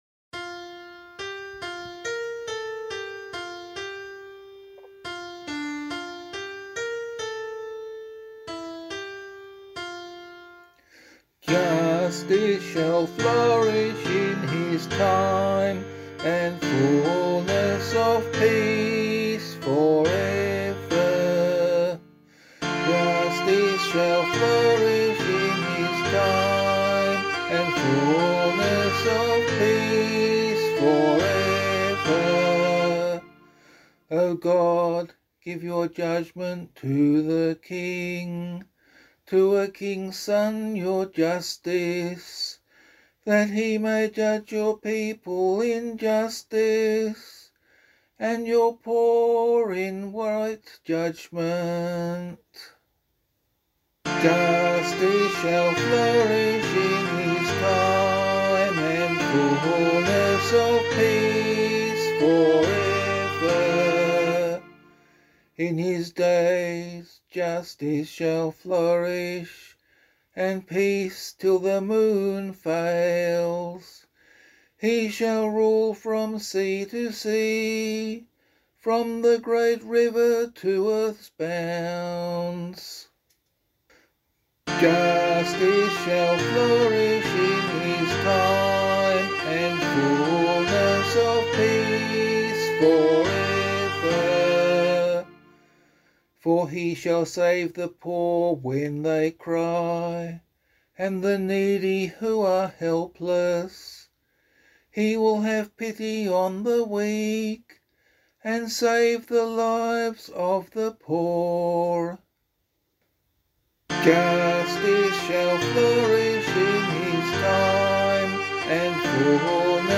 002 Advent 2 Psalm A [LiturgyShare 1 - Oz] - vocal.mp3